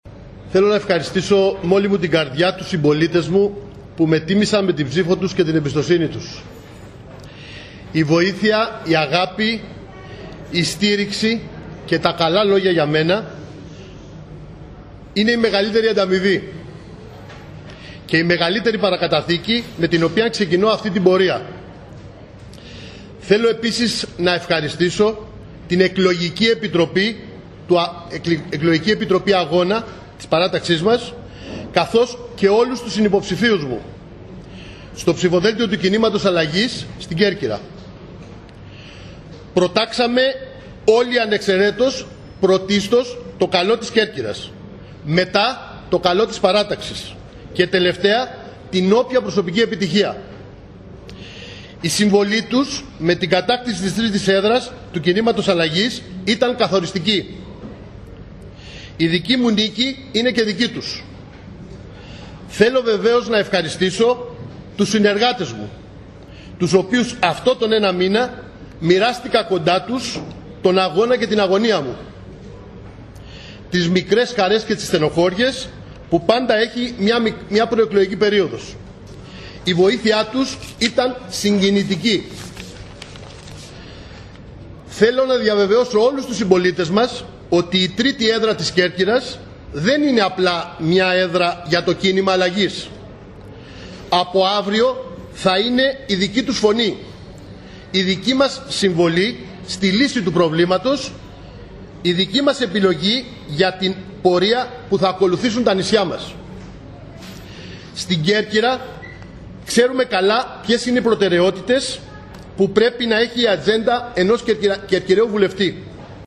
Ο κος Μπιάνκης στις δηλώσεις του που έγιναν μετα τη μία και μισή τη νύχτα δήλωσε ότι θα είναι Βουλευτής όλων των Κερκυραίων και ότι η έδρα του ανήκει σε όλη την Κέρκυρα.